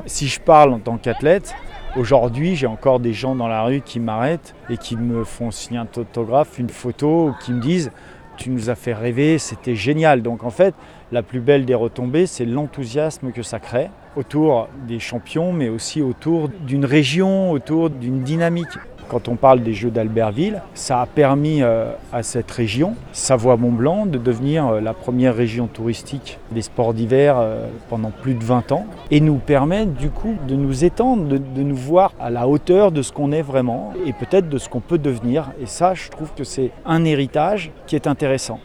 La rédaction de Radio Mont Blanc avait interviewé Edgar Grospiron à l’été 2024 alors que les Aravis se réjouissaient d’apprendre que les stations de La Clusaz et du Grand-Bornand étaient retenues pour accueillir les épreuves de ski de fond et de biathlon pour les Jeux d’hiver 2030.